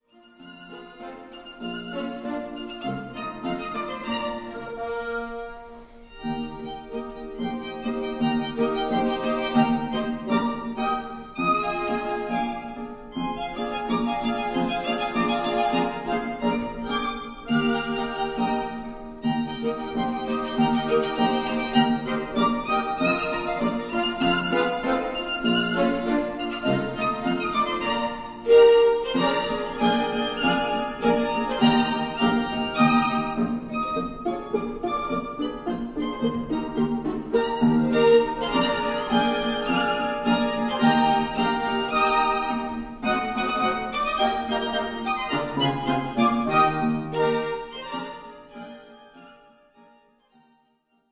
** Quartett mit Knopfharmonika
Aufgenommen live am 13.5.2007,